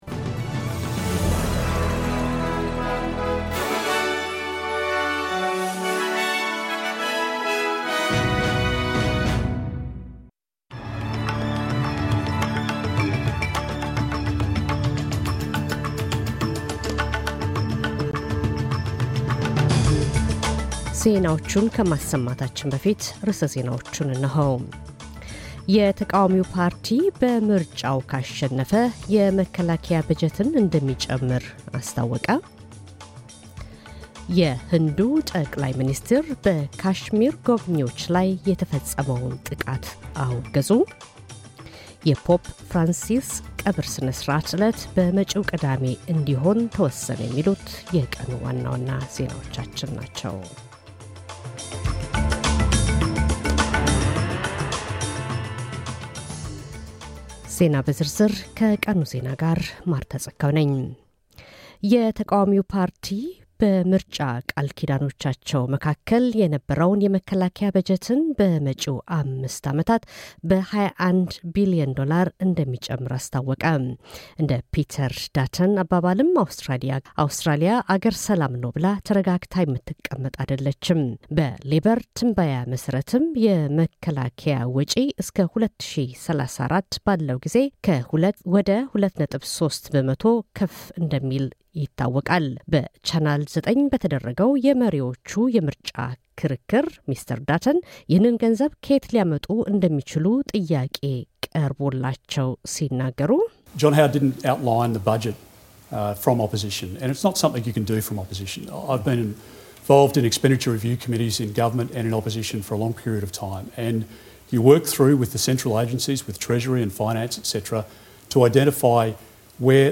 ዋና ዋና ዜናዎች